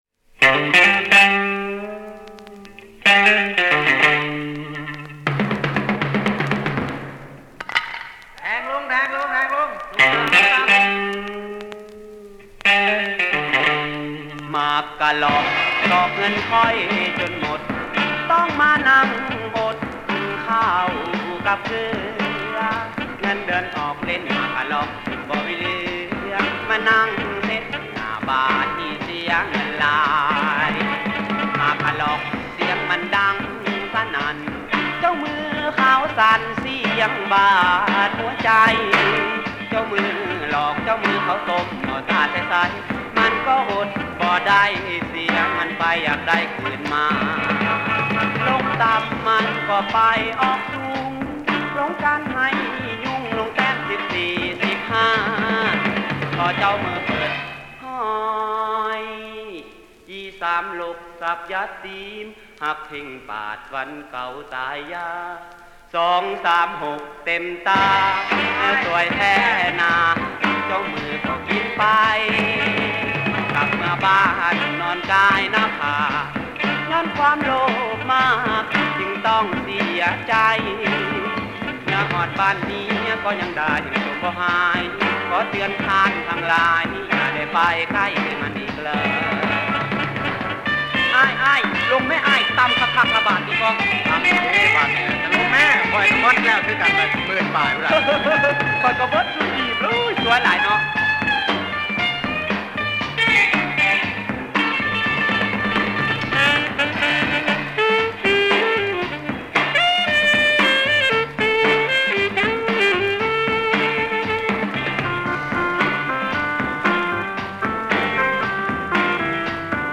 The song is sung in Thai and listed on the record in Thai.